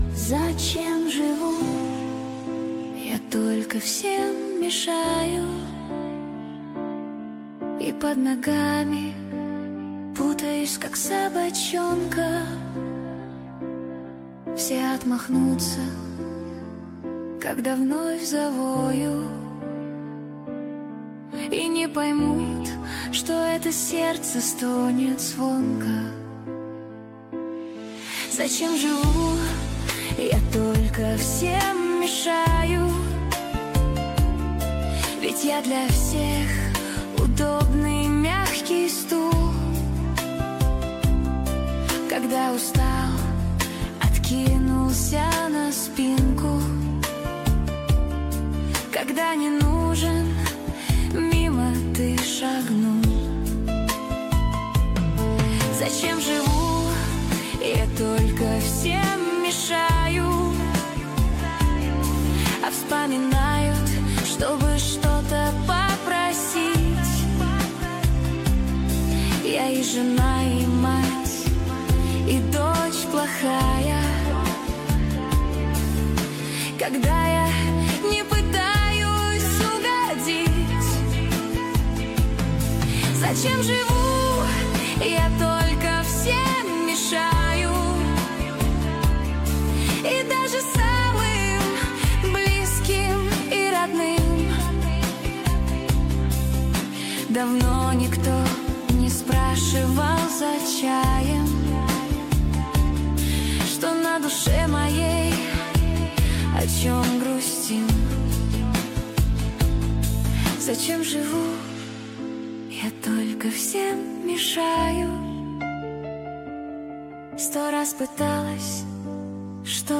Нейросеть Песни 2025
Суно ИИ кавер нейросеть